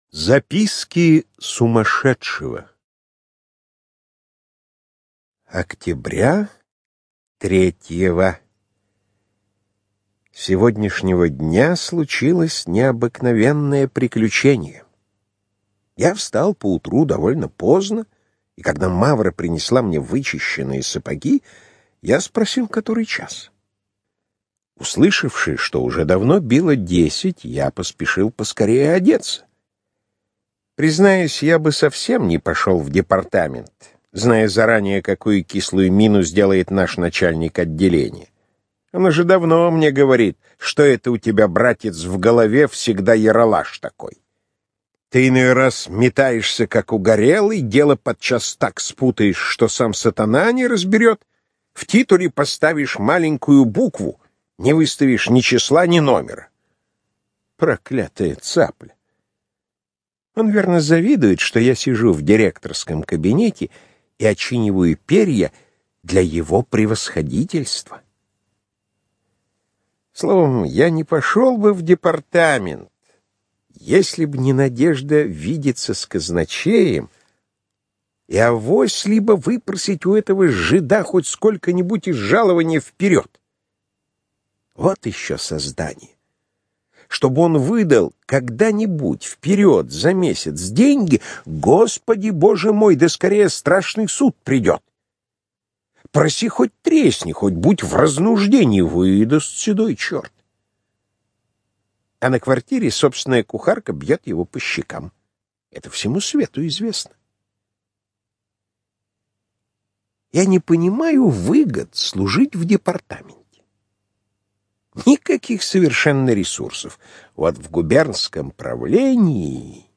ЖанрКлассическая проза